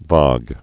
(väg)